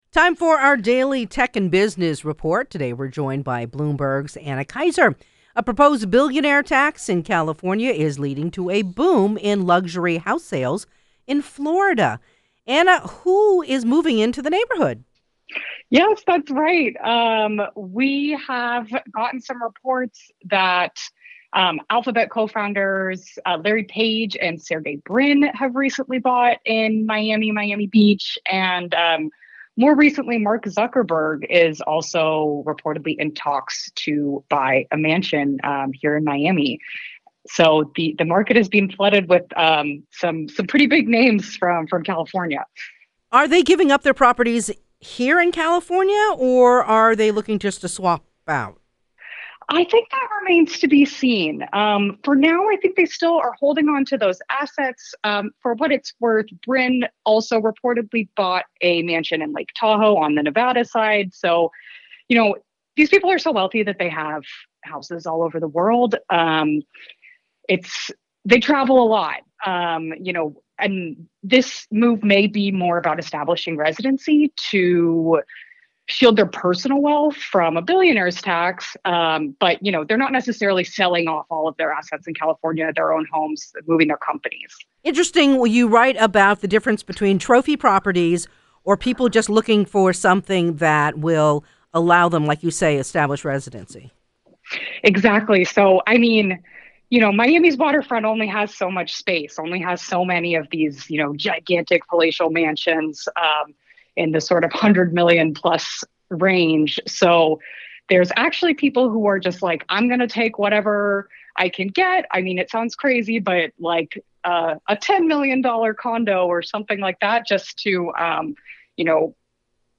This is our daily Tech and Business report.